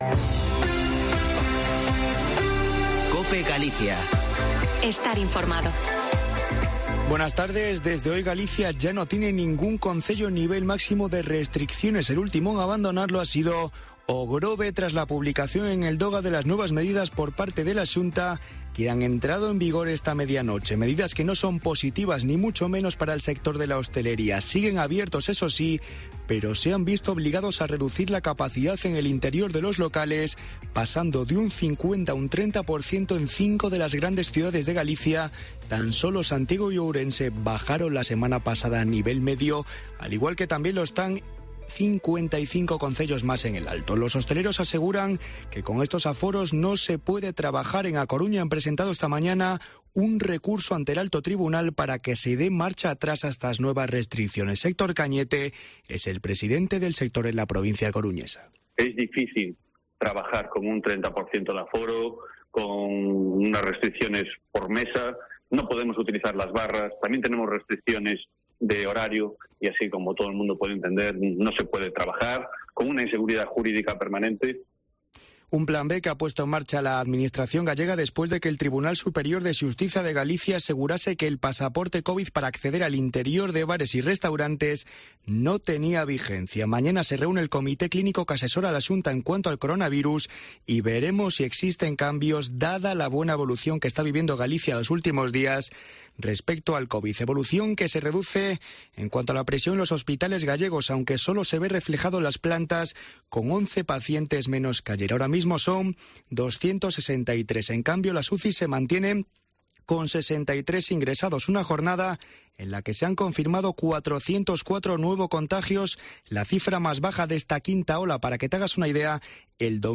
Informativo Mediodía en Cope Galicia 17/08/2021. De 14.48 a 14.58h